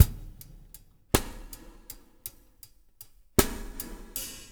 EPH STIX  -L.wav